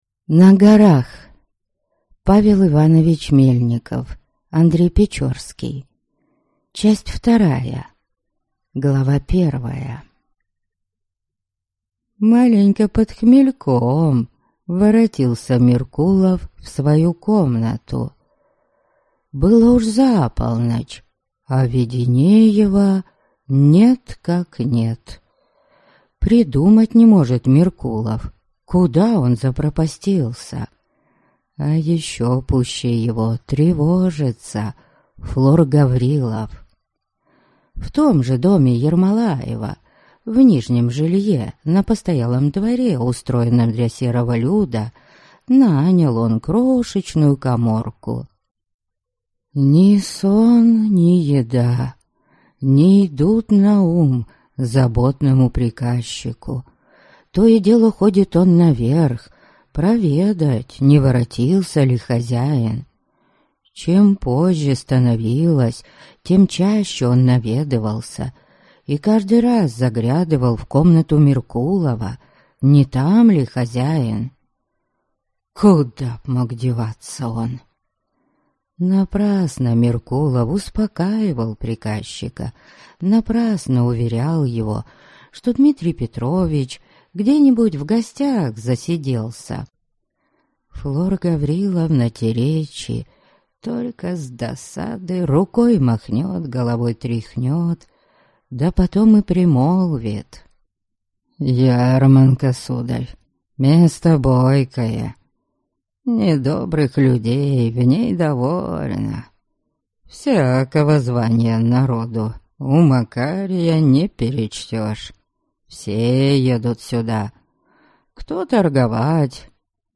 Аудиокнига На горах. Часть 2 | Библиотека аудиокниг